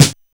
Snare (27).wav